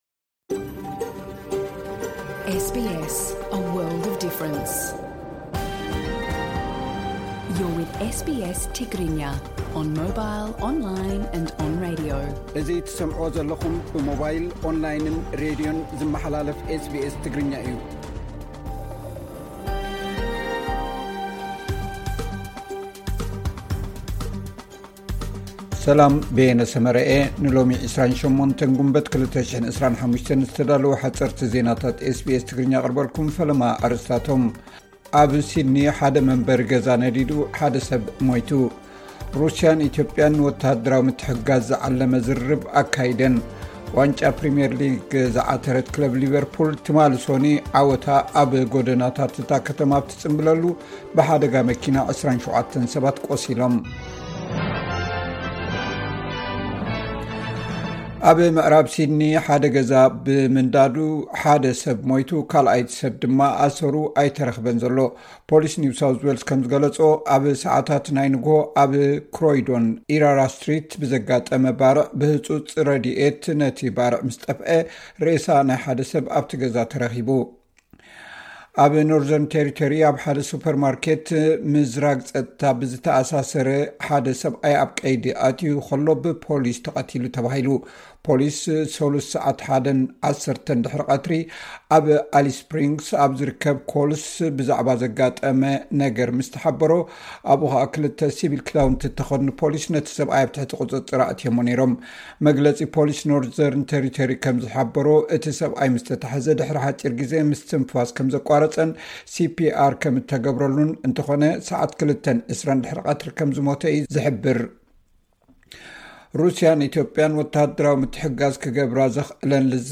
ሓጸርቲ ዜናታት ኤስ ቢ ኤስ ትግርኛ (28 ግንቦት 2025)